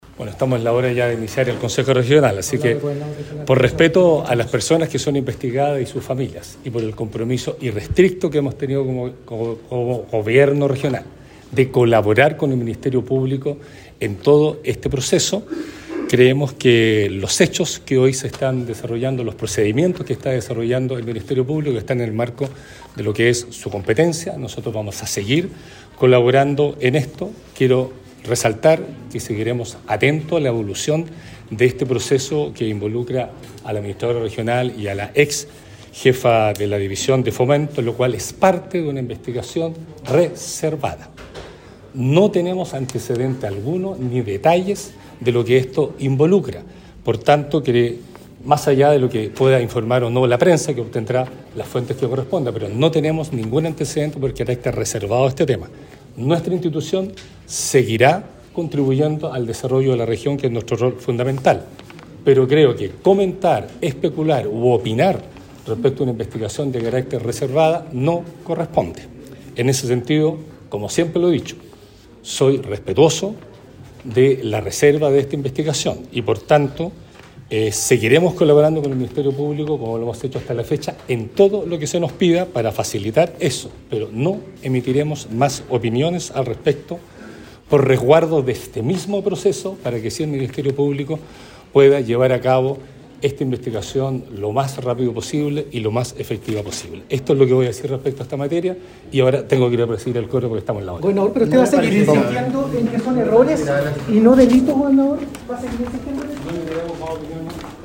En el día de hoy el gobernador regional de Los Lagos Patricio Vallespín se refirió a la detención de estas dos mujeres, una de ellas administradora de la entidad.
Esto en el momento en que se iniciaba esta mañana el consejo regional en Puerto Montt y 24 horas después que la PDI, por instrucción de la fiscalía, arrestó a estas dos personas.